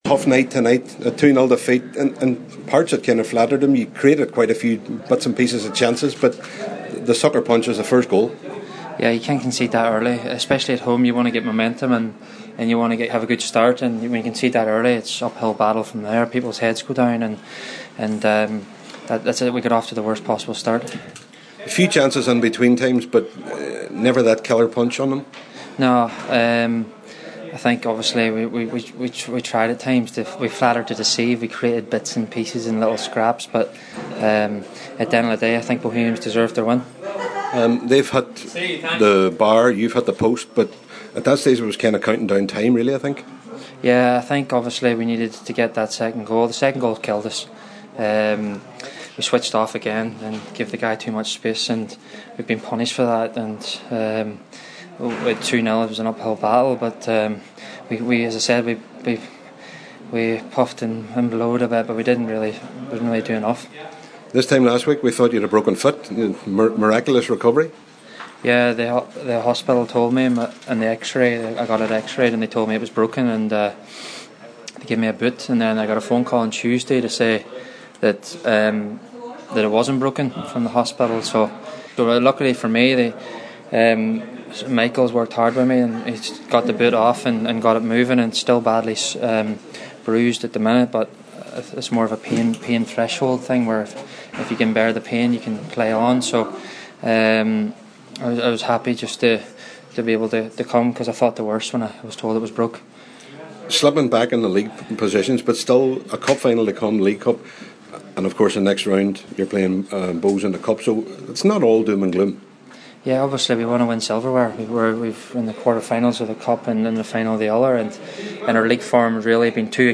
after the game…